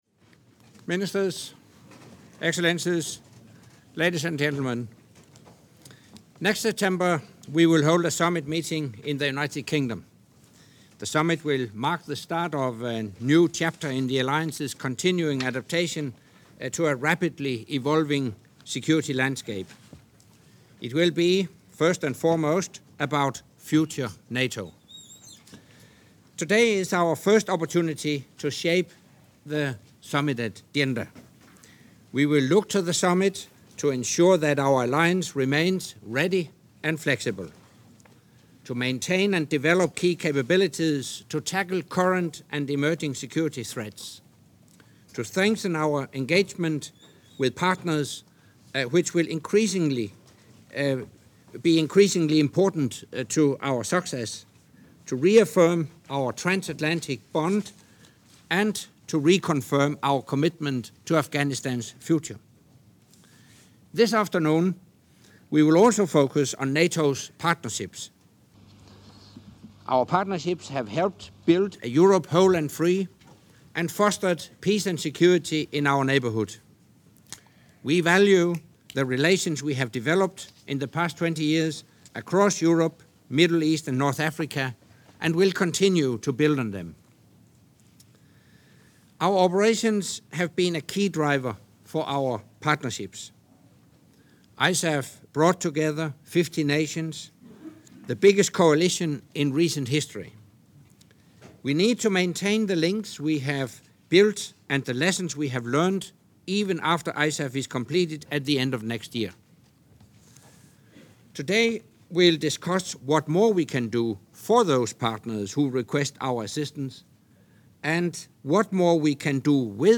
Opening remarks by NATO Secretary General Anders Fogh Rasmussen at the meeting of the North Atlantic Council in Foreign Ministers session